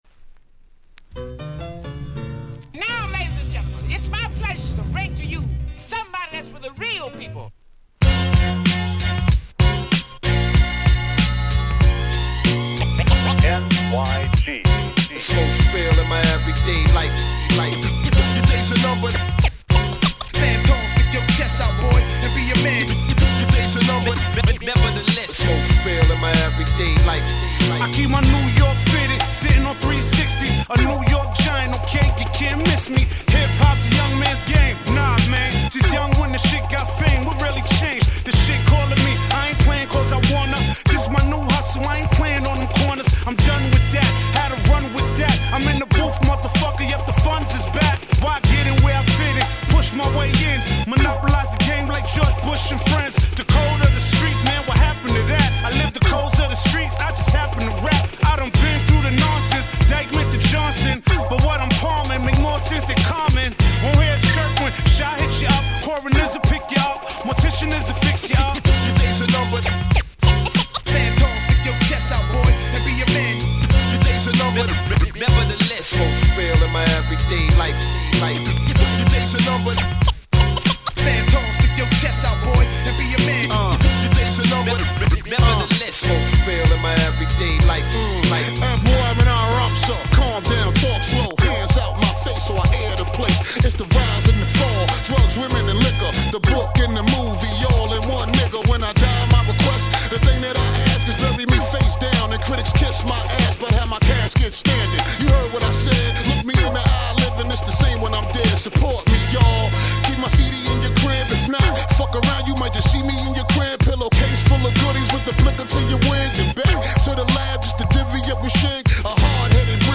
08' Suoer Dope Hip Hop !!